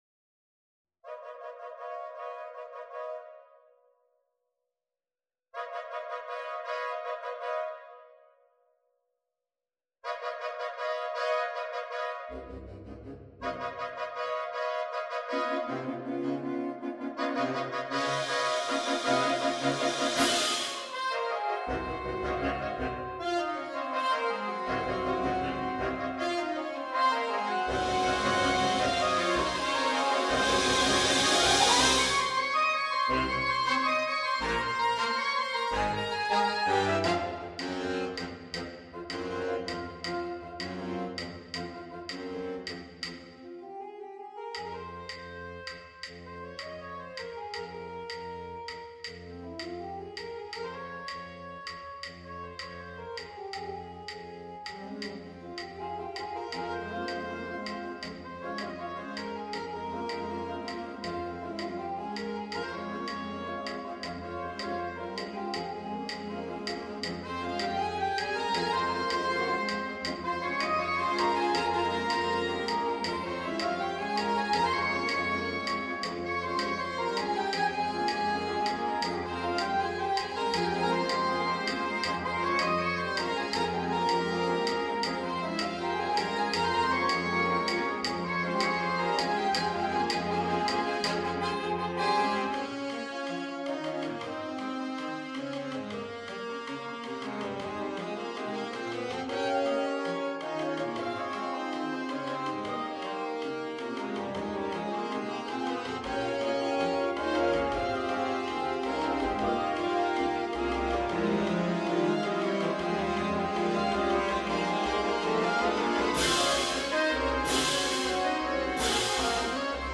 Sno/S . S . S/A . AAA . A/T . TT . BB . B/Bs . 2x Percussion
Exported from the Sibelius score using NotePerformer.